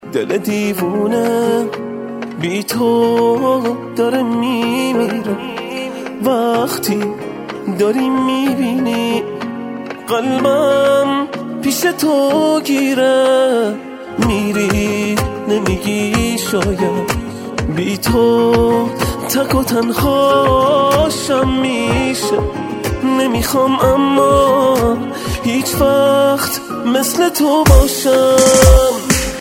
رینگتون احساسی